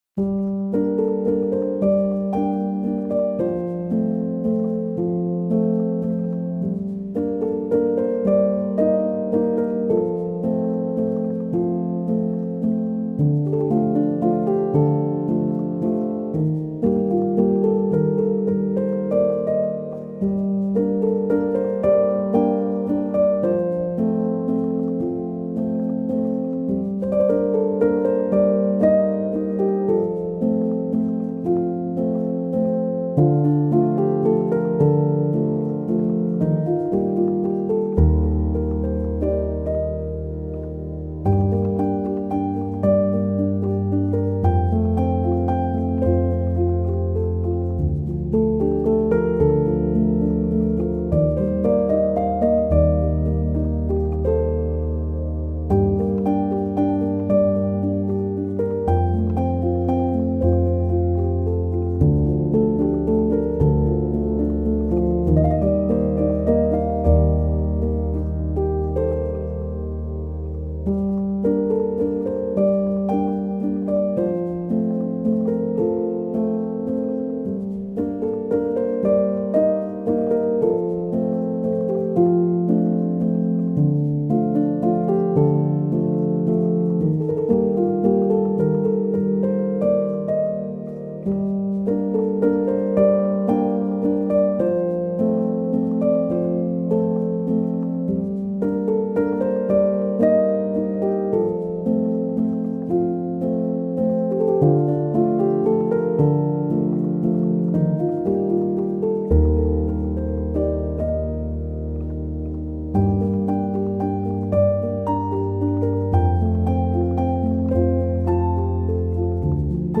آرامش بخش پیانو مدرن کلاسیک